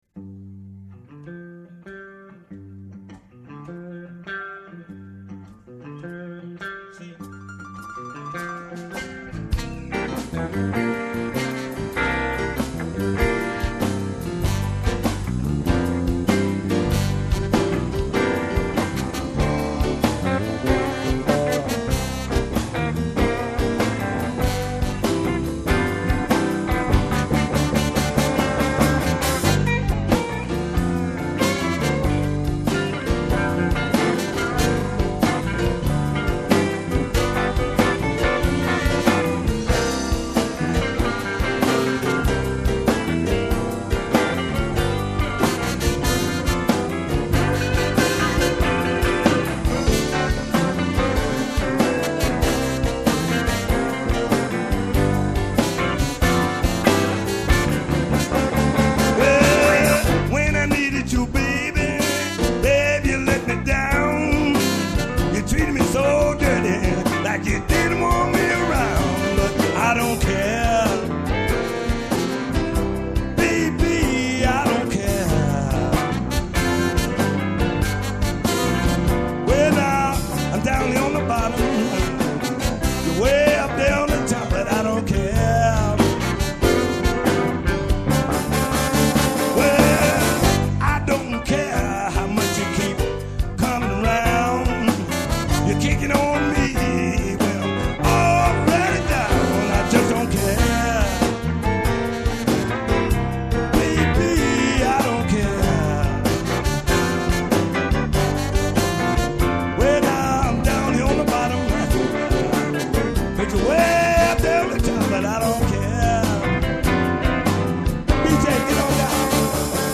Click here to download a Live-Song.
LIVE at the Blues Festival Basel